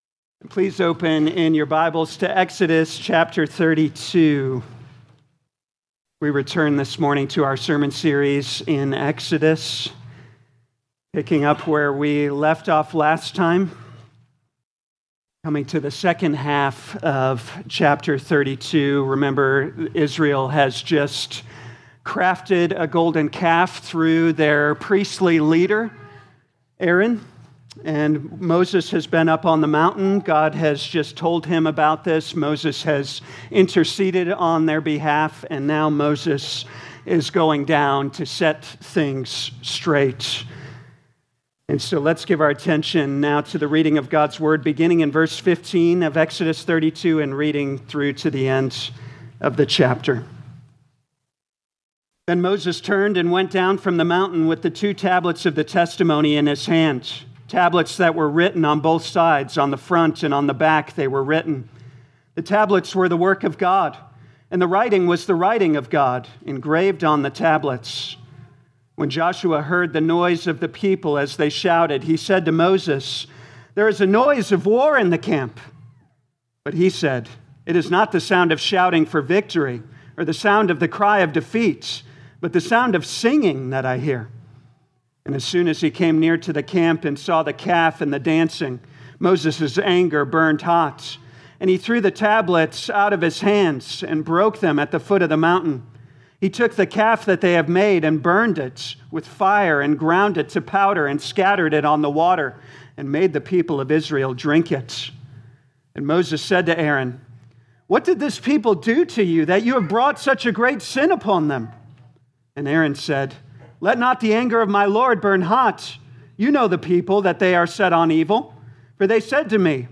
2026 Exodus Morning Service Download